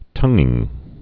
(tŭngĭng)